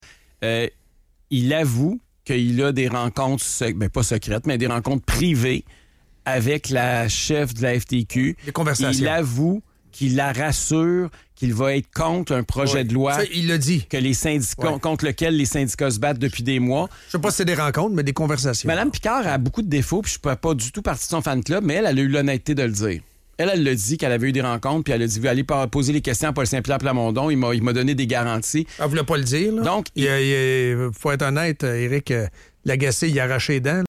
Le 26 juin 2025 en entrevue à Radio X, Éric Duhaime affirme une fois de plus que PSPP « avait une entente avec la FTQ » dans le cadre de laquelle « il serait contre le projet de loi du ministre du travail pour faire plaisir à la FTQ. »
Note: l’entrevue n’est plus disponible sur le site de Radio X, nous avons donc extrait le passage grâce au site d’archivage Wayback Machine.